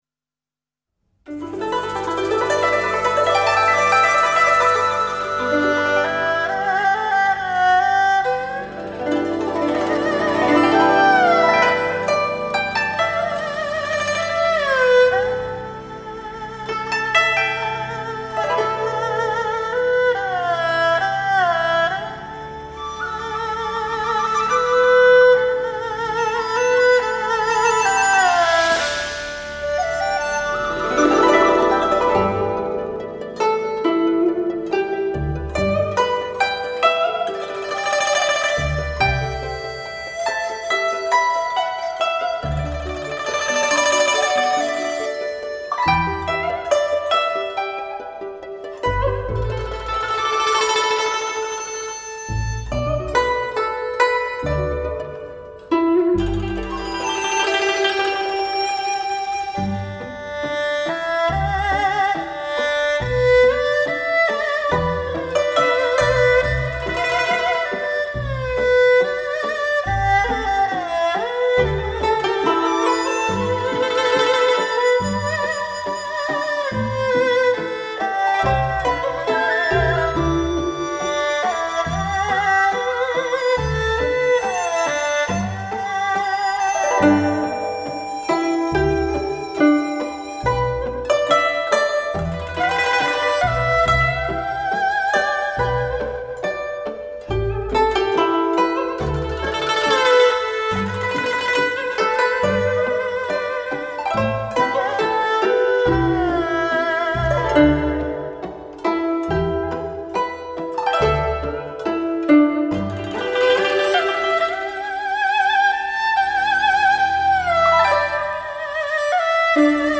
HIFI极品，母带后期德国精制，古筝VS二胡面对面。
古色古香，酝酿古味，惊艳现世。
音效标准音色逼真，将最原汁原味的美好声响还原到极至。